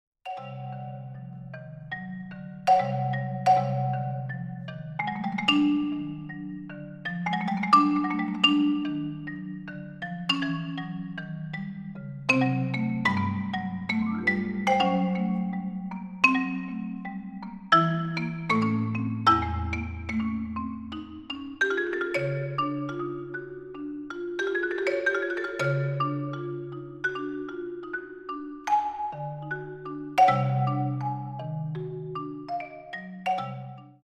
Unrelenting in intensity 1:59